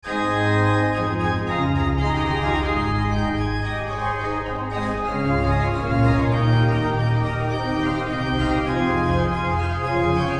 Der Klang der Ehrlich-Orgel in der Stadtkirche hat seine Wurzeln im mainfränkischen Orgelbau der Barockzeit. In seiner Farbigkeit wirkt er auf heutige Ohren meist auf das erste eher ein wenig fremd und archaisch.